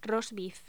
Locución: Roast beef
voz
Sonidos: Voz humana